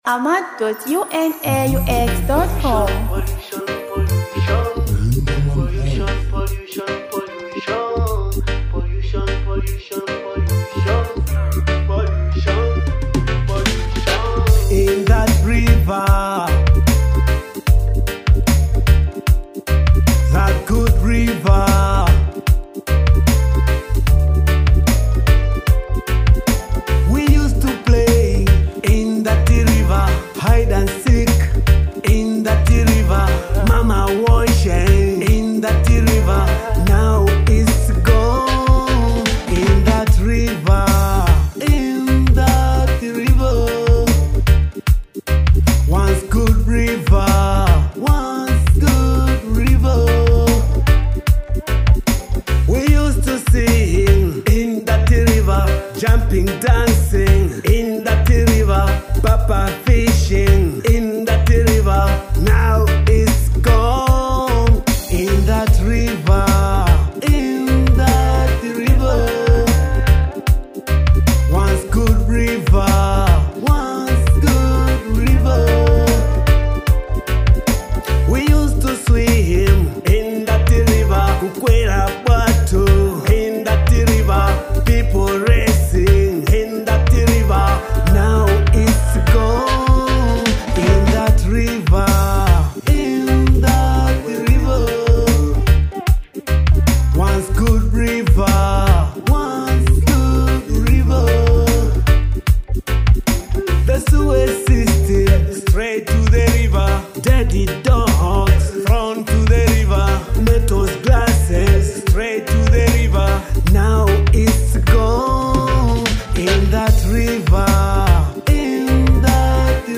Through compelling lyrics and evocative melodies